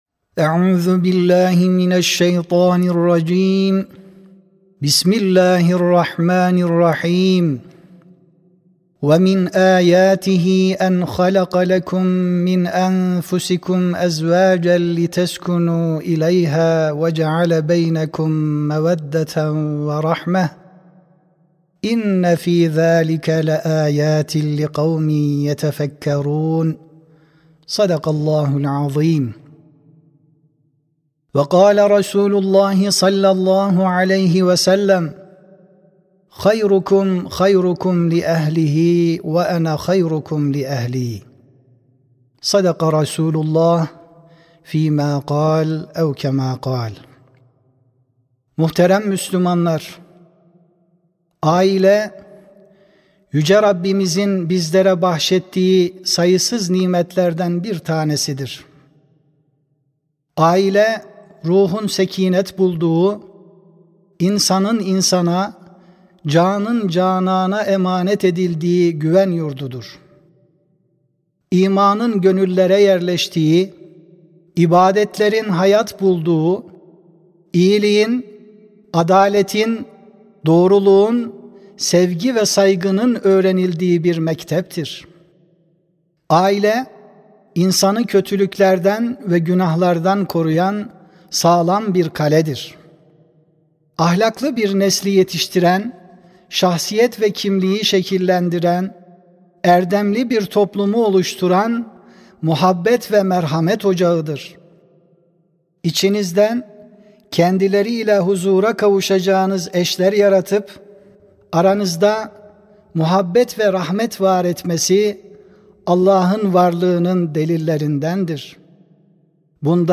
31 Ekim 2025 Tarihli Cuma Hutbesi
Sesli Hutbe (Ailede Huzurun Kaynağı, Merhamet ve Muhabbet).mp3